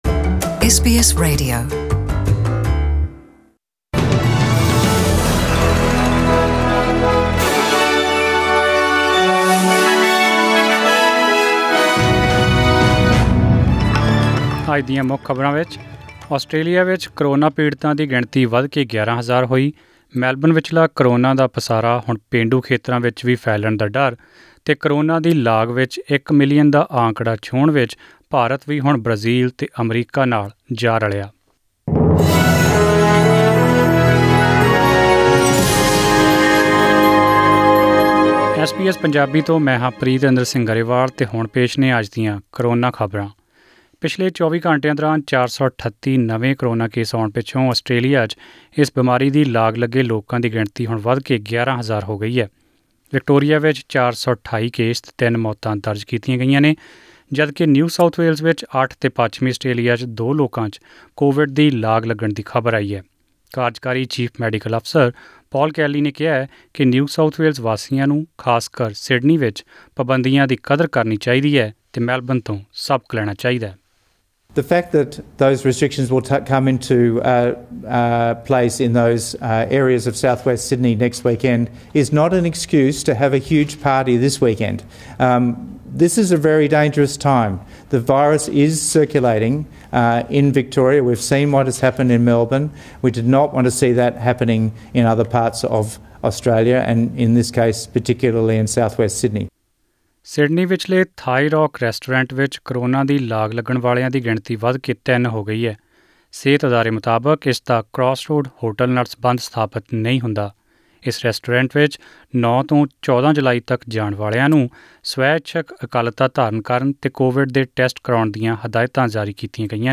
Australian News in Punjabi: 17 July 2020